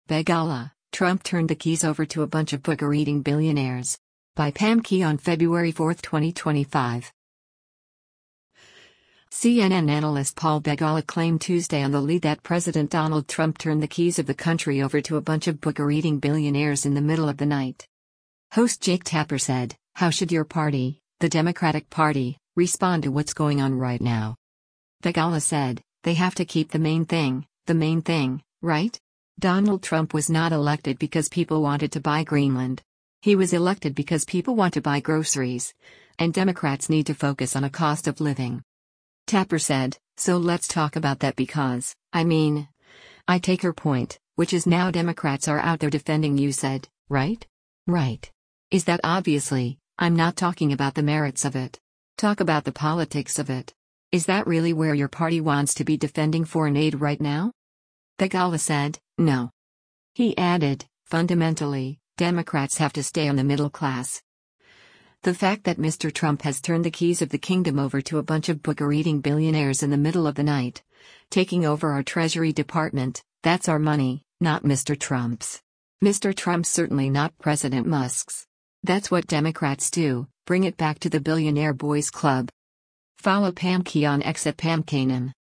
CNN analyst Paul Begala claimed Tuesday on “The Lead” that President Donald Trump turned the keys of the country over to a “bunch of booger-eating billionaires in the middle of the night.”
Host Jake Tapper said, “How should your party, the Democratic Party, respond to what’s going on right now?”